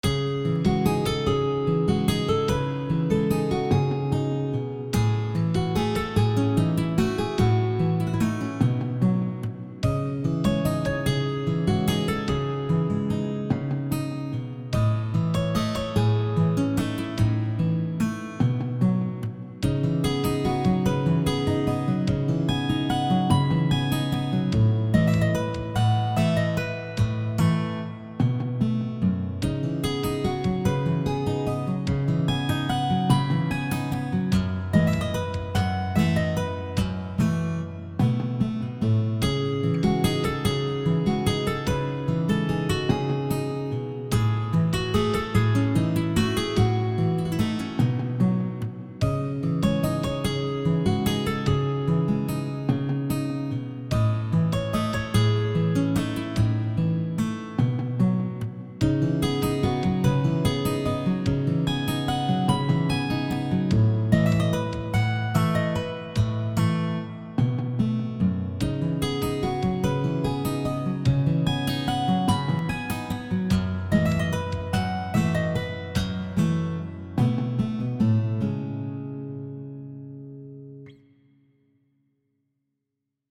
A cold guitar music.